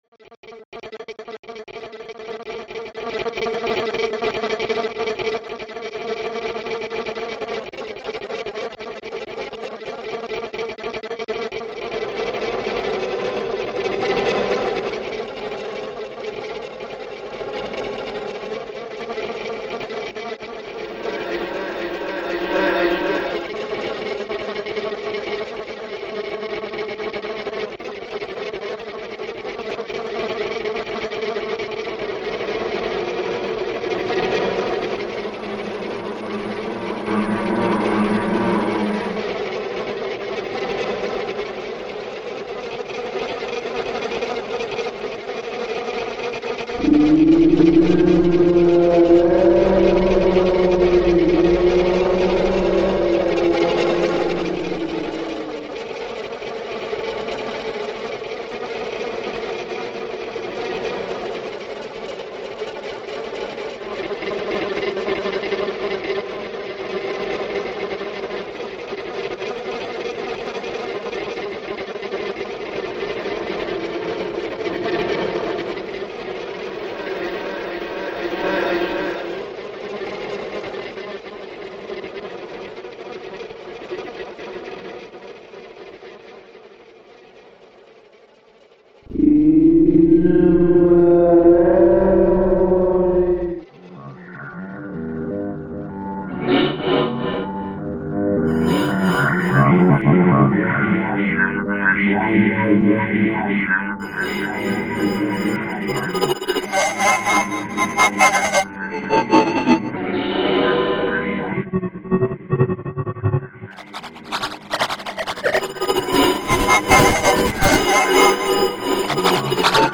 ELECTRONIC COMPUTER MUSIC
Io ho utilizzato solo la registrazione di questa frase e l'ho elaborata principalmente con cool-edit e il software della Pulsar II.